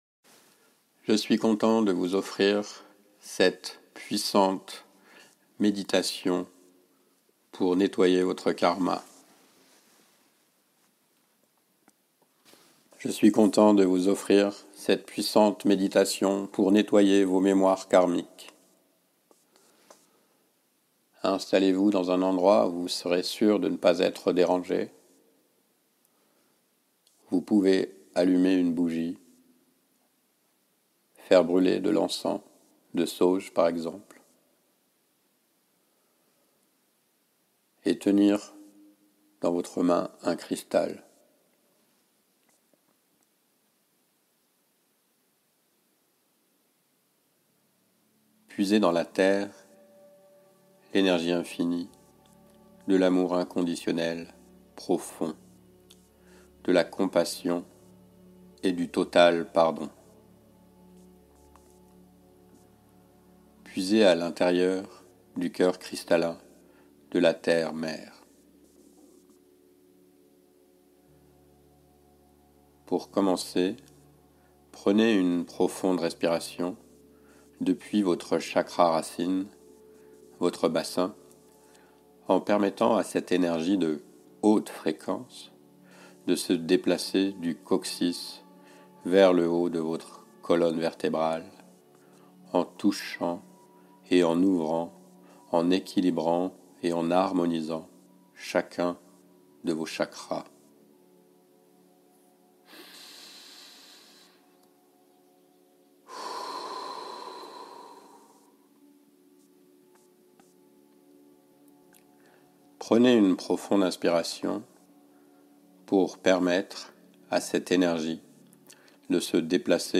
Meditation-de-nettoyage-des-memoires-karmiques.mp3